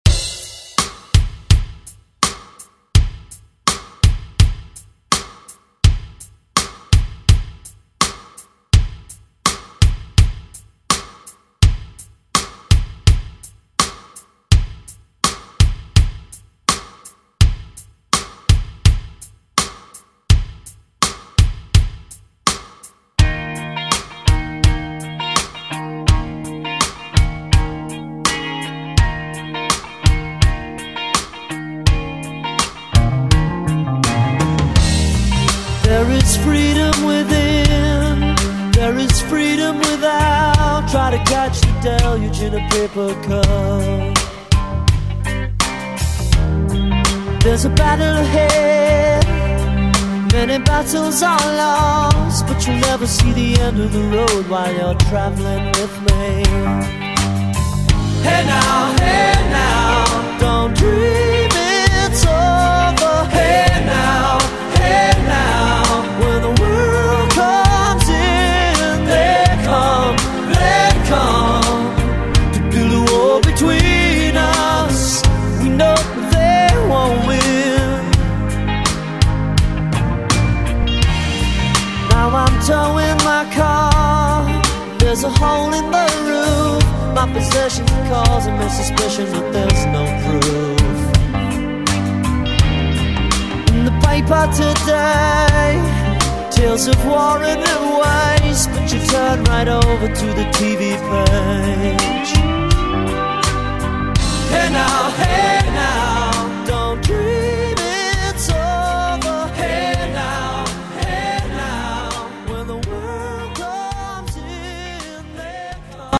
80s Throwback Electronic Pop Rock Music
129 bpm
Genre: 80's